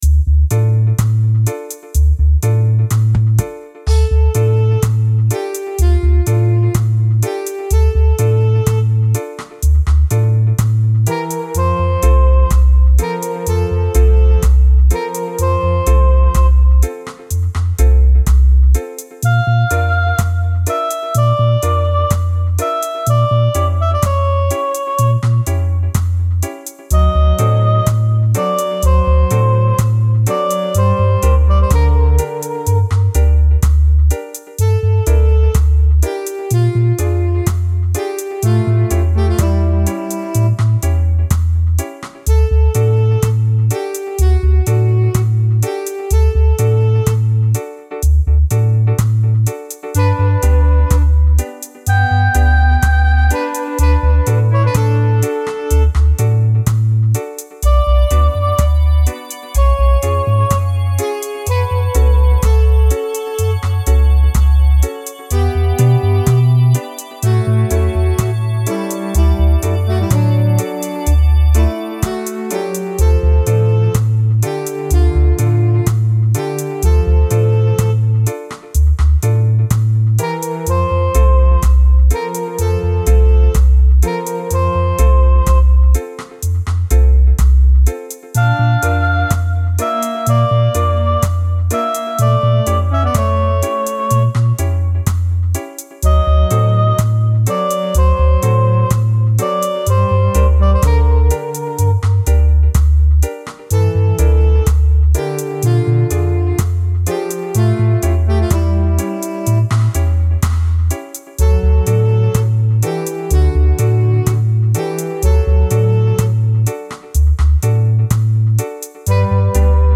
Level 7 (Be careful of volume.).